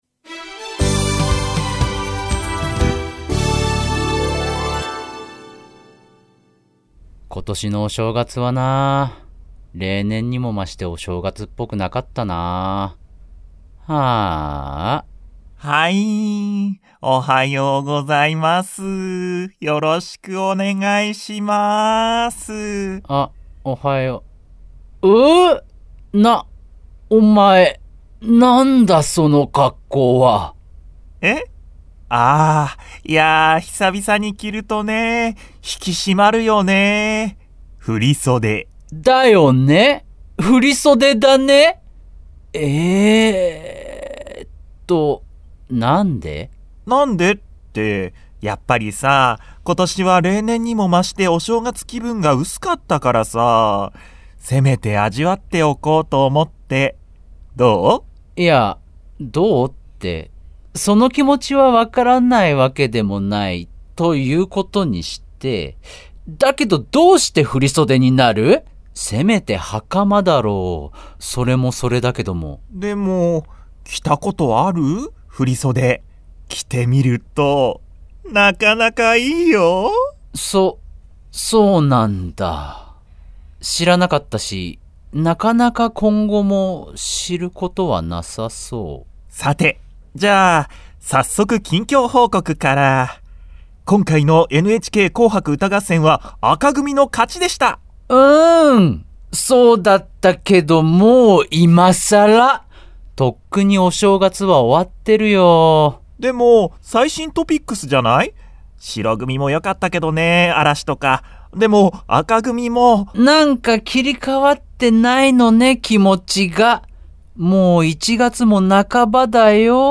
コント『いつまでお正月気分』